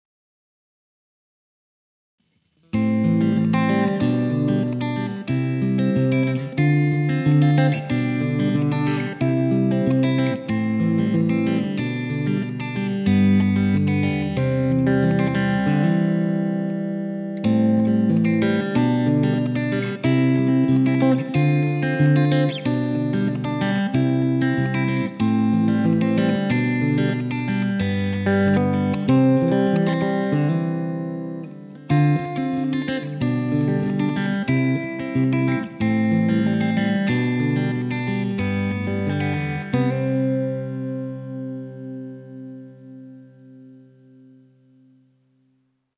Genre: Rock, Funk Rock, Blues